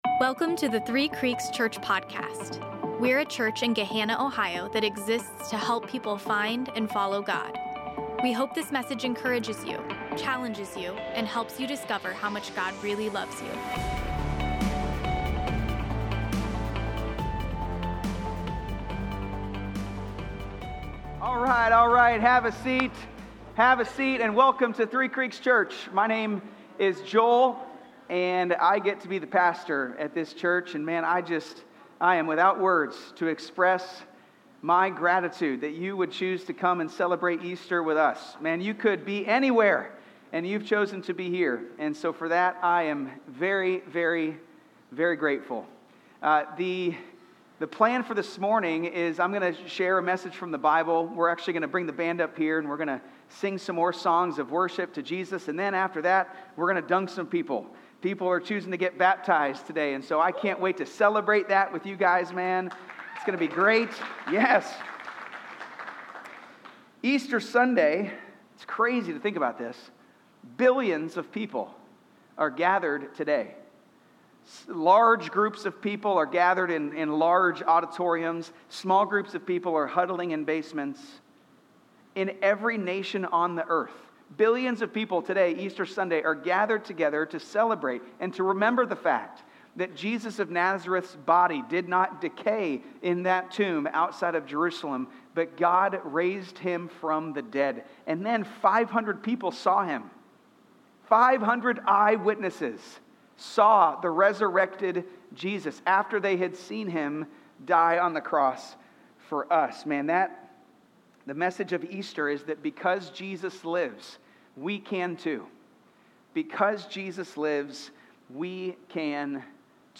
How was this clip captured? Easter-POD-4.20.25.mp3